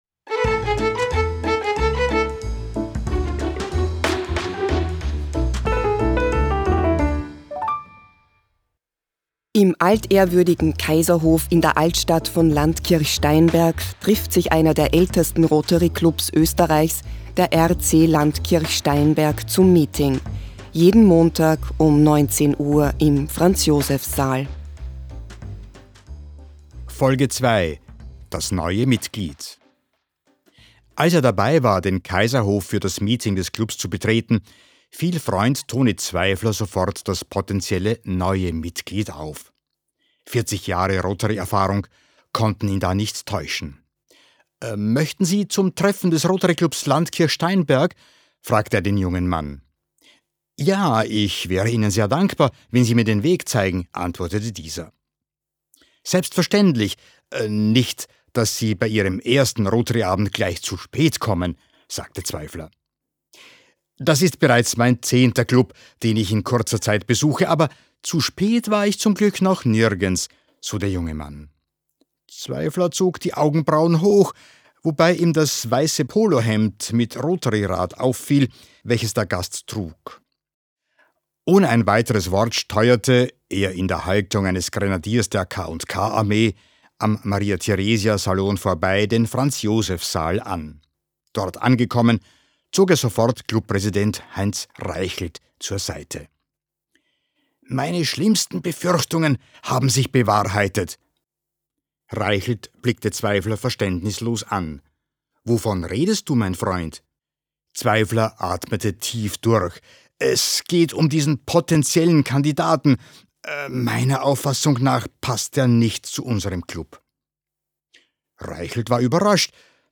Audio-Comedy